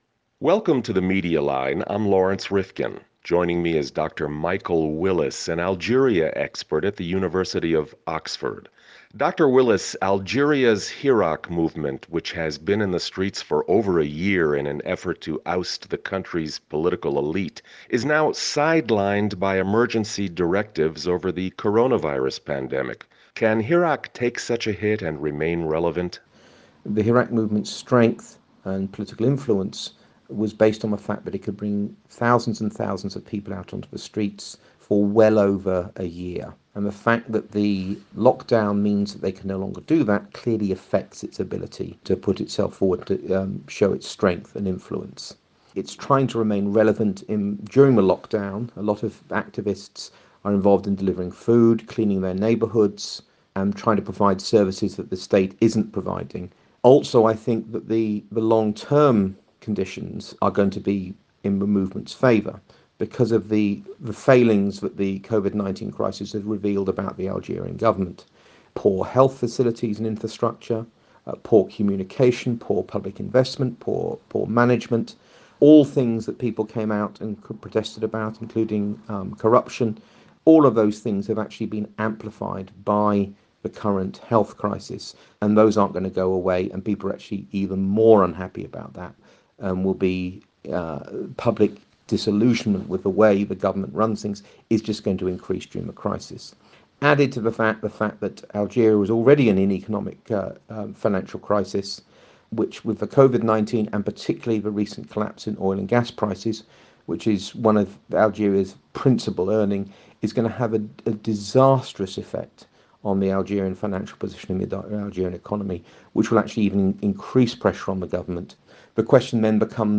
The Media Line Out of Sight – but Not Out of Anger (AUDIO INTERVIEW) - The Media Line